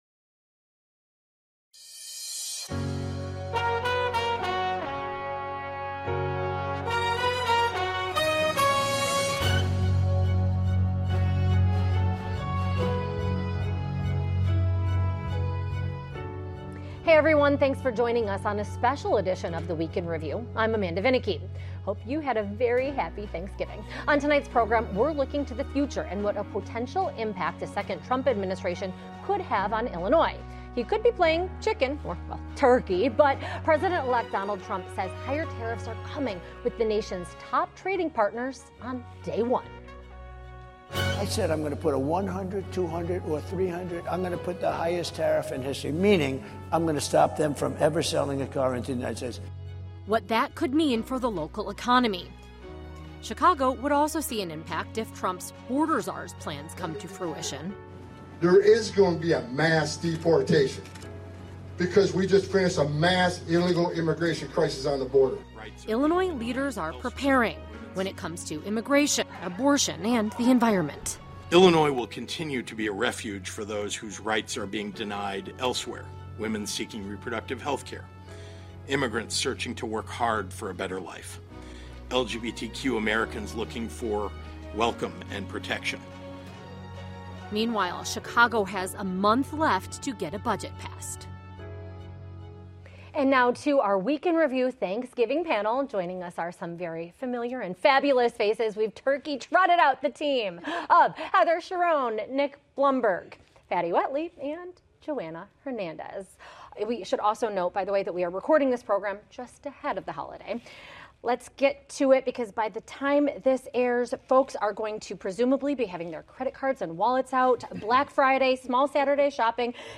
For this “Week in Review” special, our WTTW News reporters look to the future as Chicago — and the rest of the nation — prepares for a second Trump presidency.